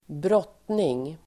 Uttal: [²br'åt:ning]